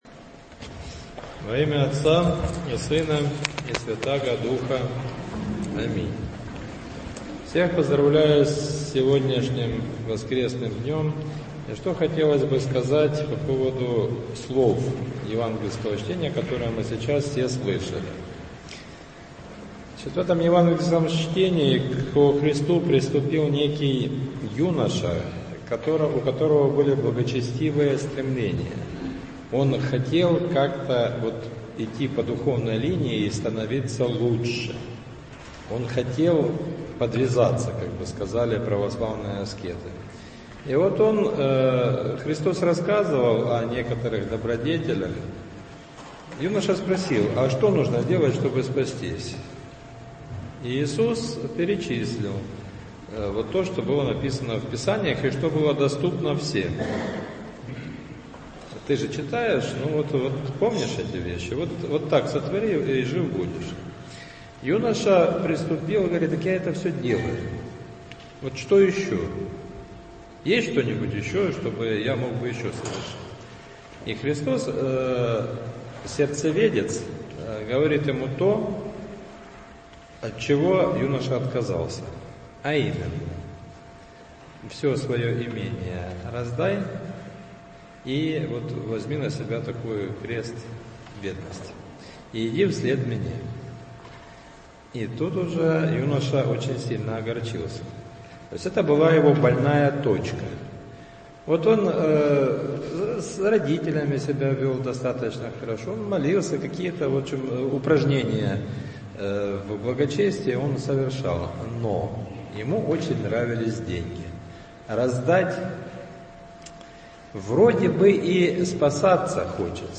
Проповедь в Двадцать пятую неделю по Пятидесятнице.
В воскресение, 12 декабря, на Божественной литургии читался отрывок из Евангелия от Луки (18, 18-27).